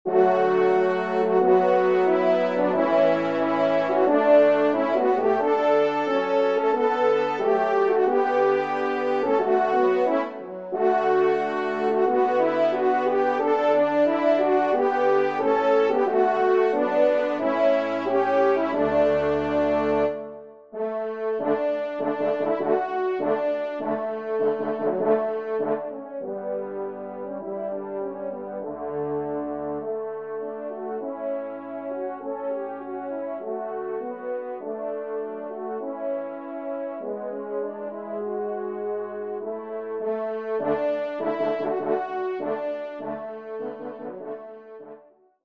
Pupitre 4° Cor  (en exergue)